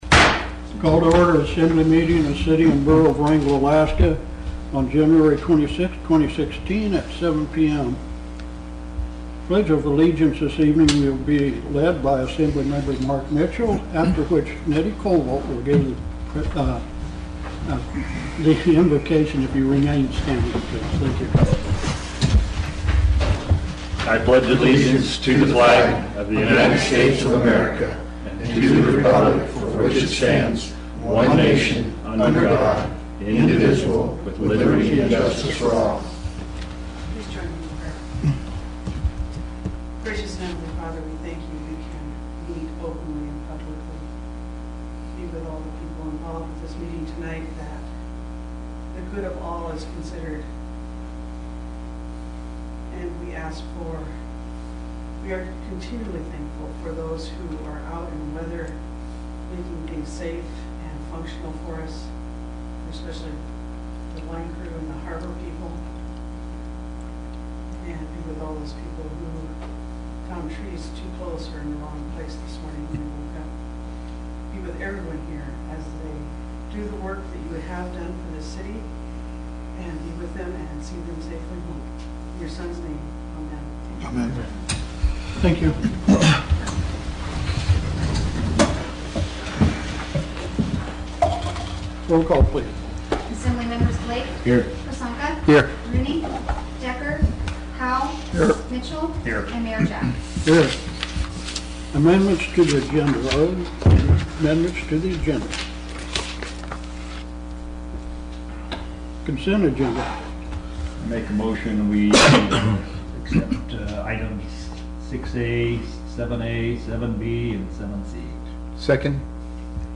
Wrangell's Borough Assembly held its regular meeting Tuesday, Jan. 26, 2016 in the Assembly Chambers.
City and Borough of Wrangell Borough Assembly Meeting AGENDA January 26, 2016 – 7:00 p.m. Location: Assembly Chambers, City Hall